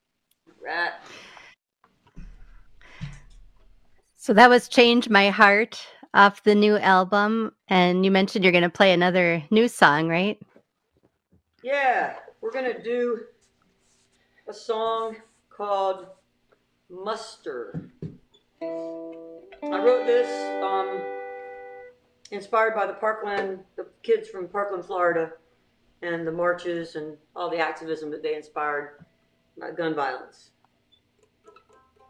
(captured from facebook)
03. interview (0:32)